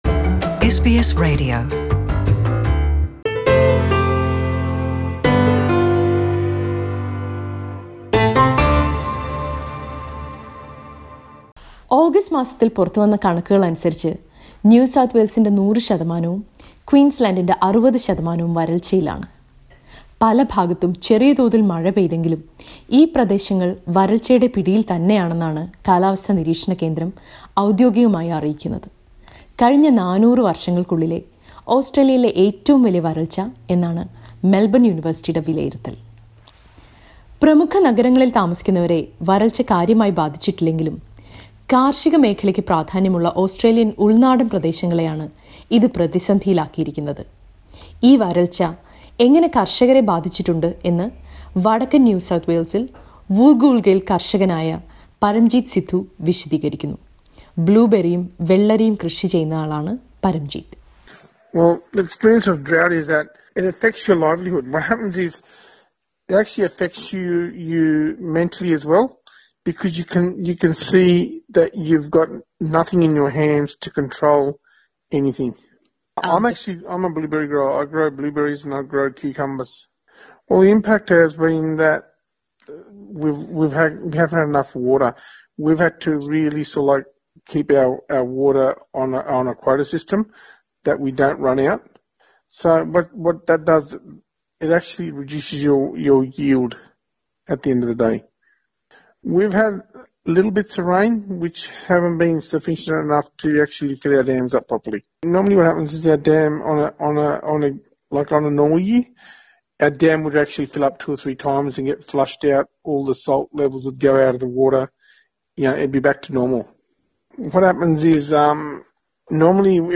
The recent drought in various parts of Australia has affected many in regional areas. It is said that this recent drought can affect the people living in urban areas too. Listen to a report on that...